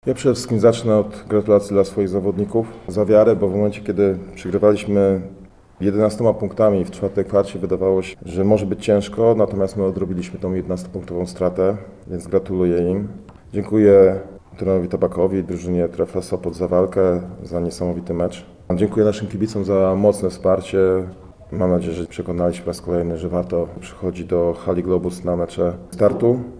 na konferencji prasowej